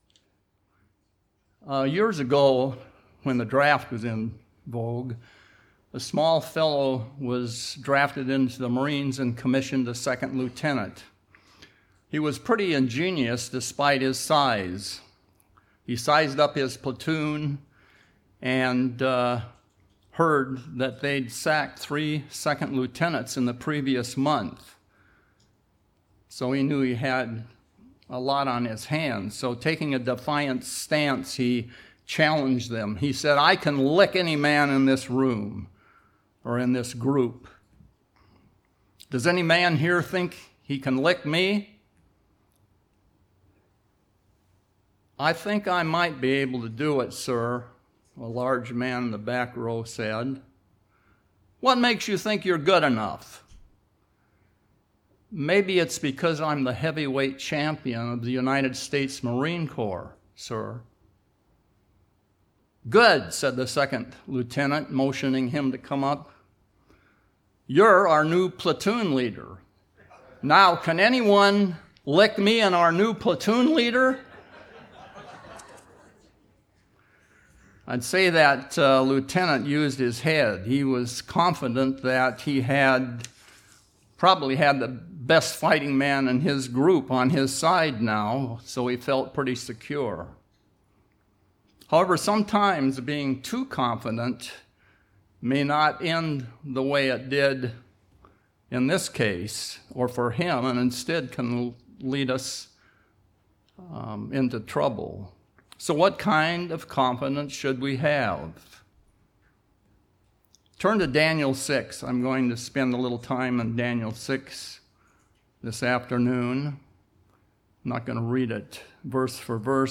Sermon
Given in Seattle, WA